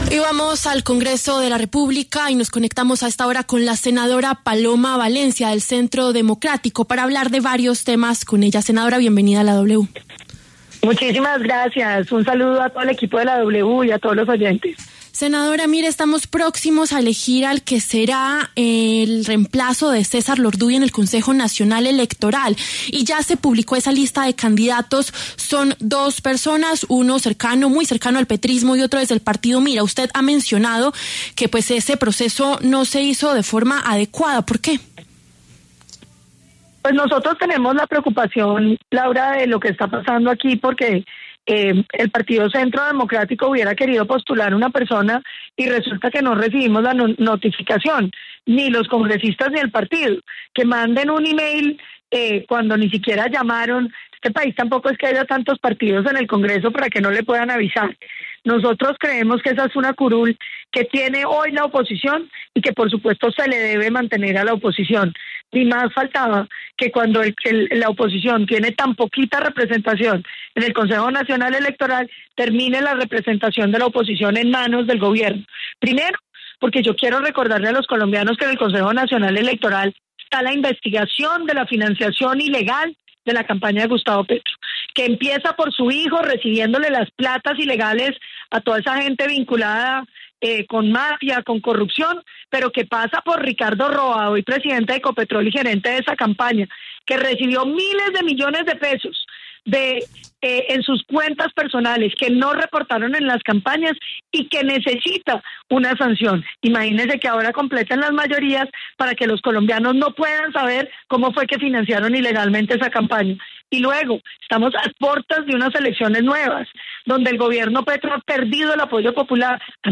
La senadora Paloma Valencia pasó por los micrófonos de La W y habló sobre la polémica por la elección del nuevo magistrado del Consejo Nacional Electoral (CNE), quien reemplazará a César Lorduy.